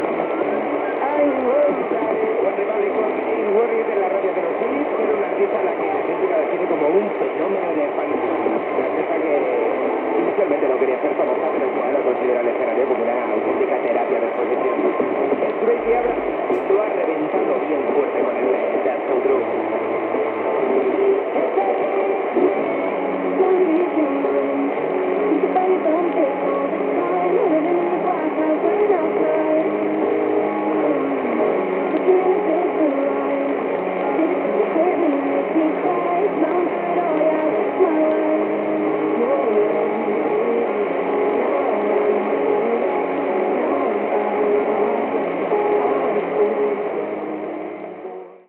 Comentari del tema escoltat, indicatiu de la ràdio i tema musical
Musical
Qualitat de so defectuosa.
Enregistrament fet des de Barcelona.